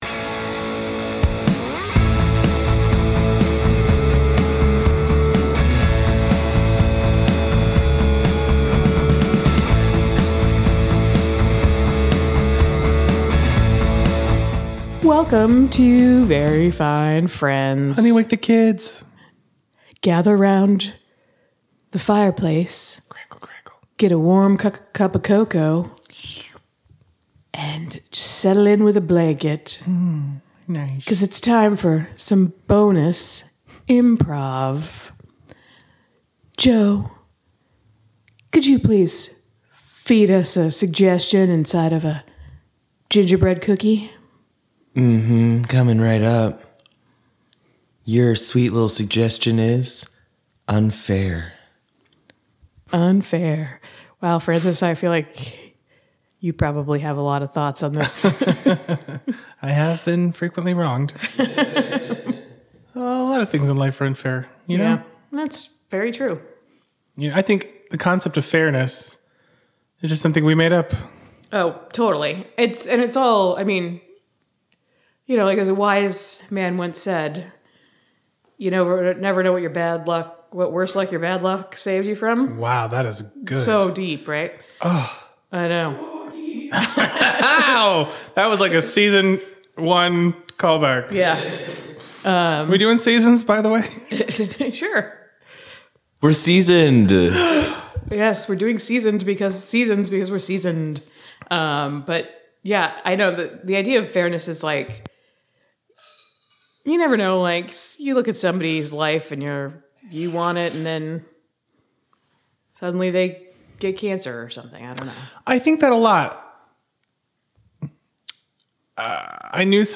Bonus IMPROV - I'm Going To Wish That You Are Cured - Pocket Casts